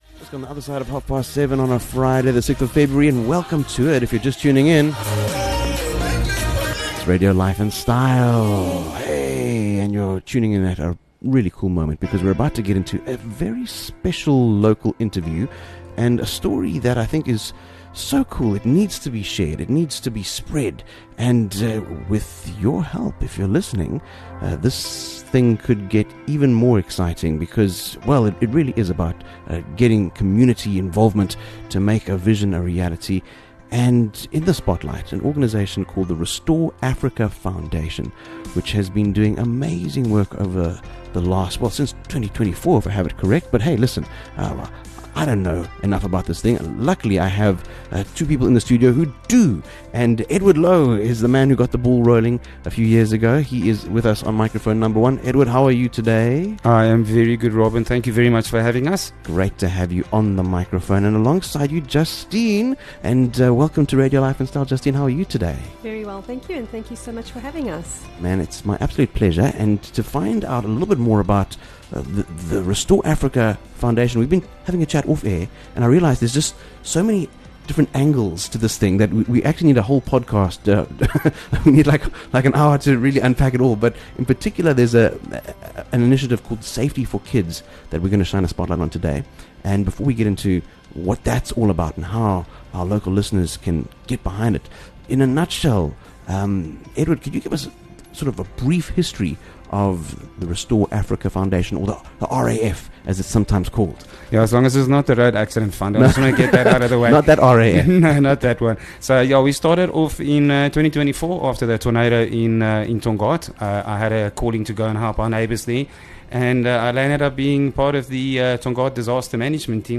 This interview